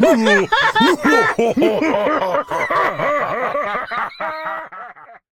Giants_laught.ogg